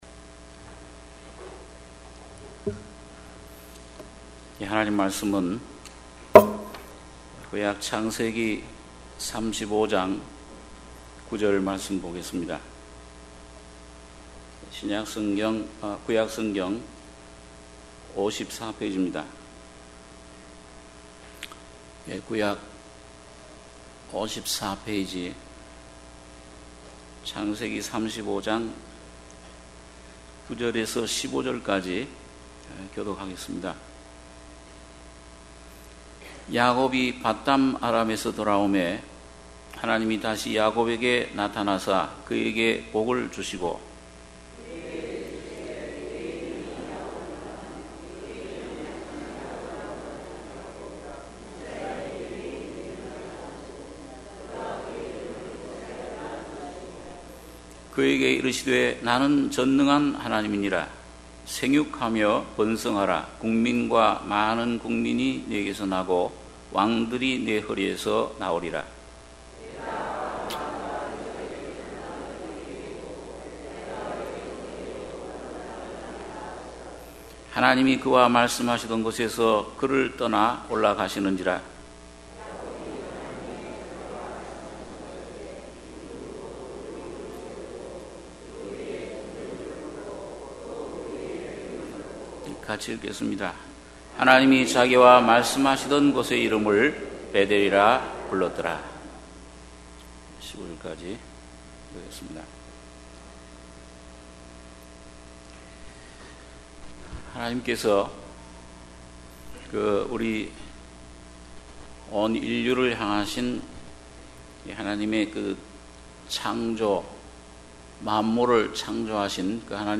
주일예배 - 창세기 35장 9-15절